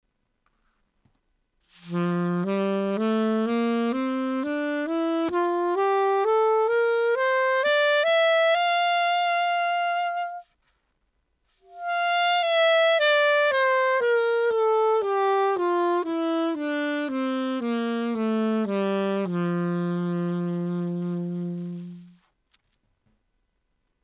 Tramontin Classic alto mouthpiece
The Tramontin Classic Mouthpiece for alto sax is the perfect choice for saxophonists seeking a well-balanced sound, full of character, with a natural response across all dynamics.
Its refined design allows for smooth execution and a rich, full-bodied tone—ideal for soloists as well as those looking for a classic and versatile sound.